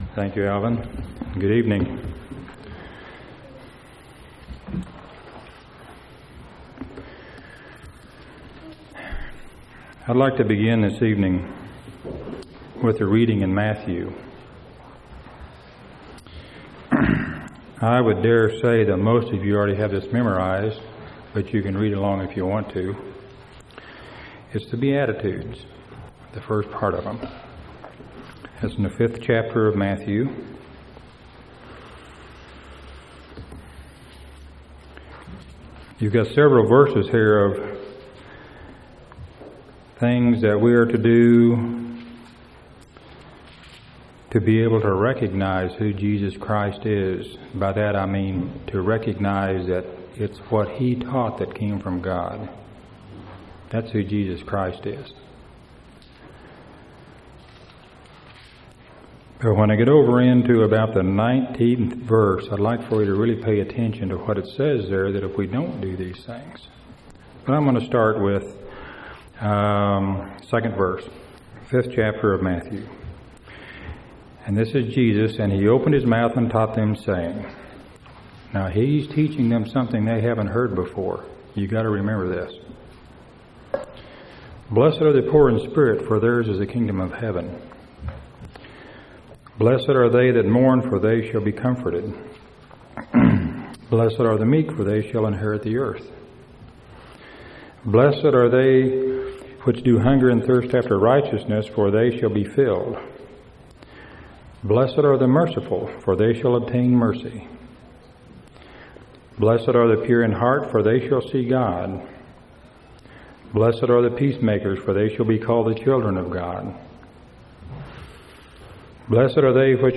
2/27/2000 Location: Temple Lot Local Event